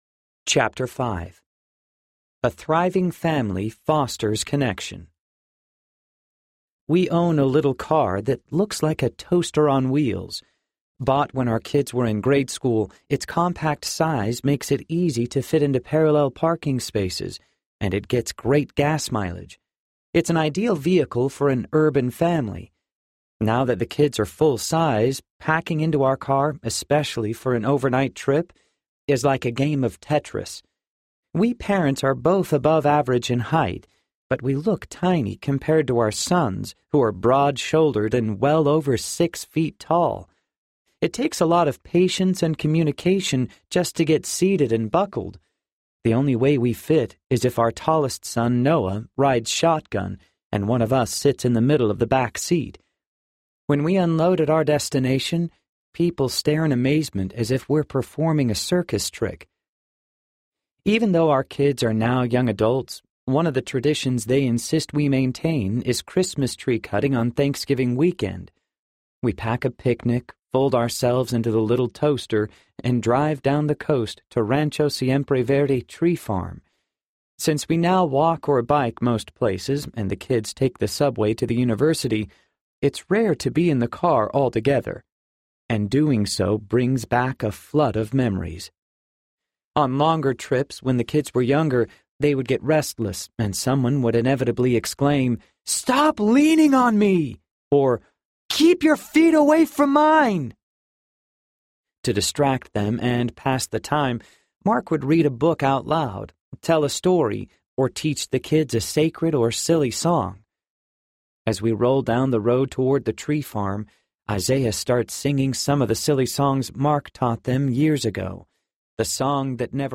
Belonging and Becoming Audiobook
7.6 Hrs. – Unabridged